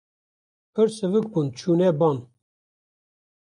Pronounced as (IPA) /bɑːn/